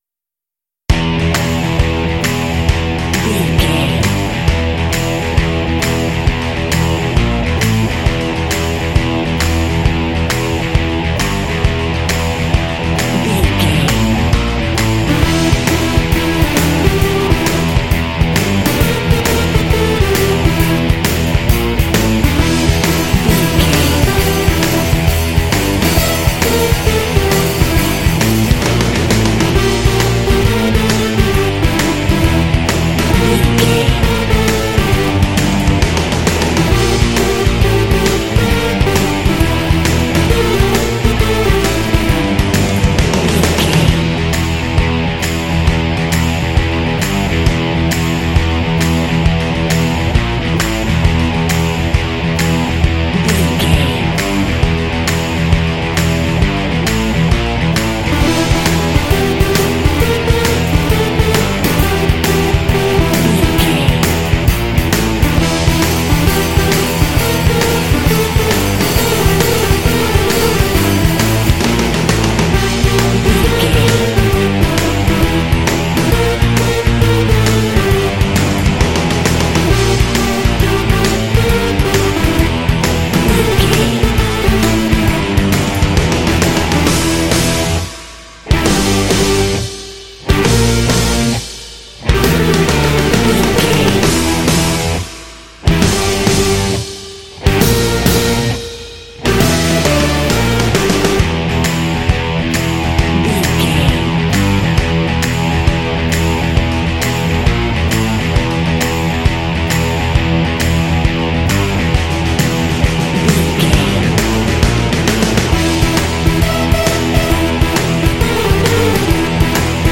Aeolian/Minor
funky
energetic
bass guitar
electric guitar
drums
synthesiser
alternative rock